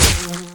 Fly_bug_hit.ogg